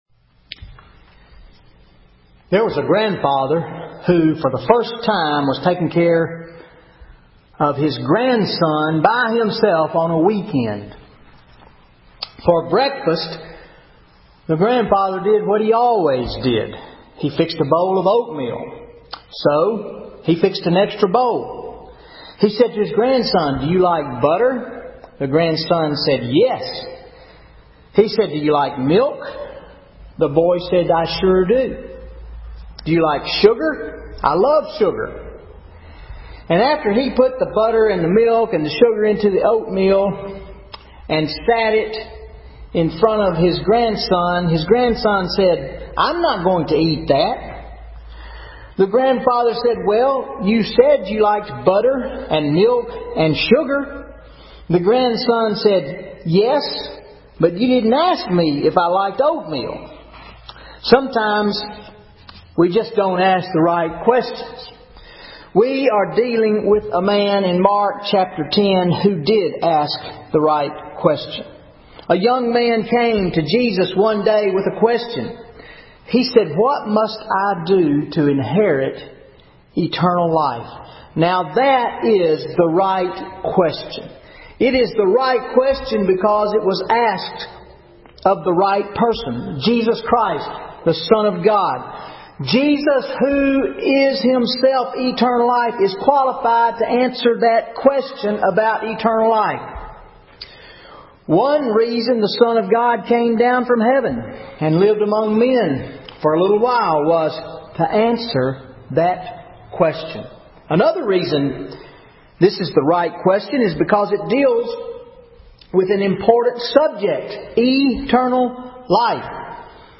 Sermon March 17, 2013 Mark 10:17-31 What must I do to inherit eternal life?